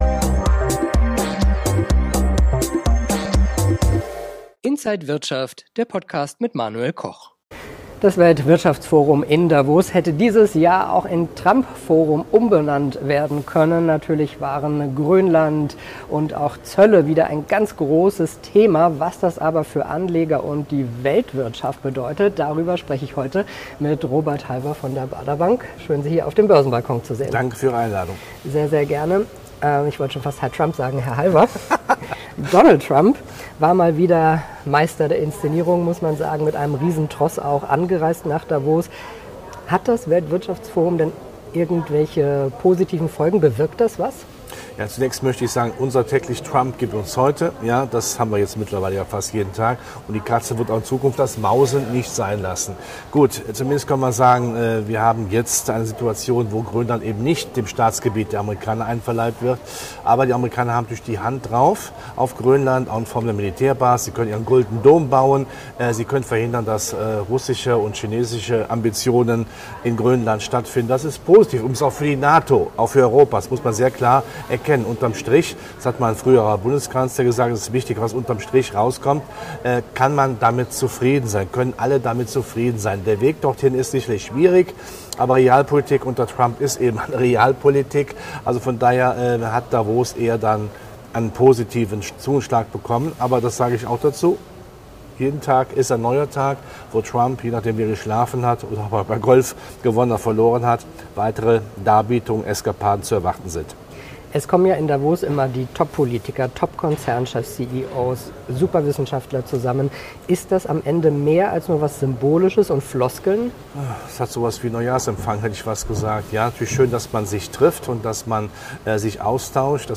Alle Details im Interview von Inside
an der Frankfurter Börse